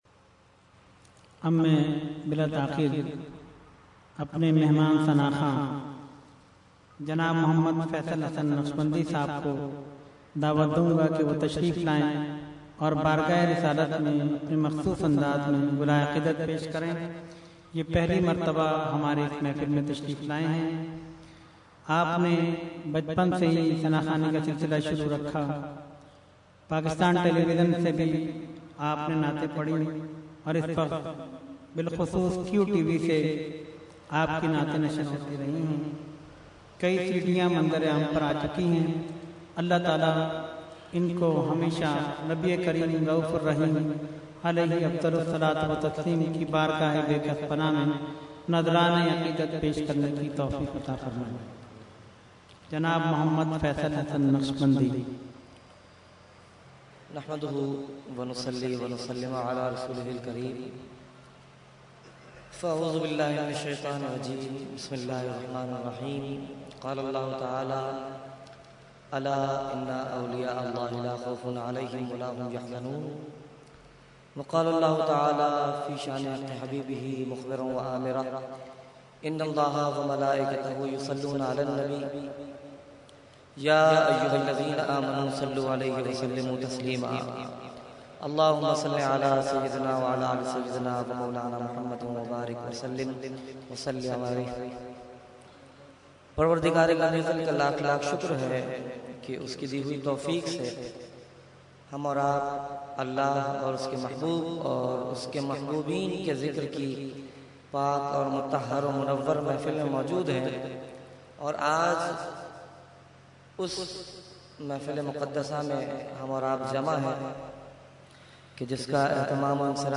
Category : Naat | Language : UrduEvent : Urs Ashraful Mashaikh 2012